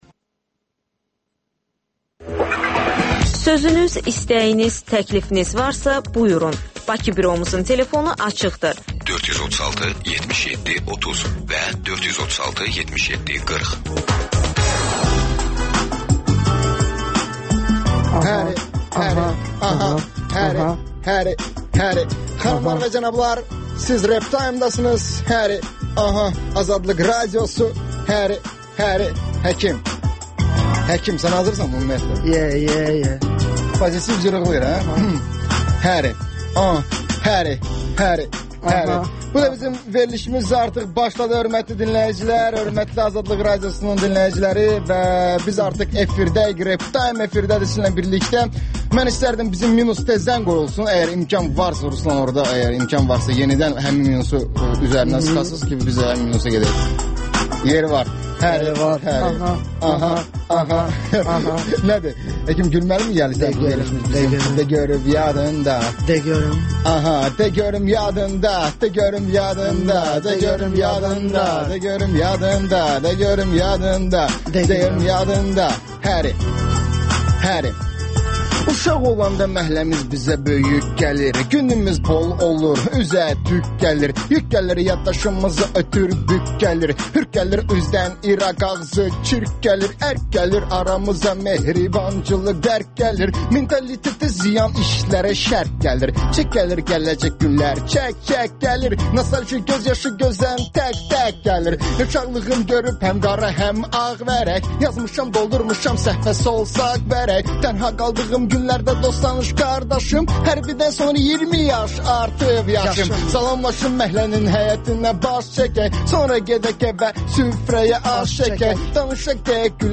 Gənclərin musiqi verilişi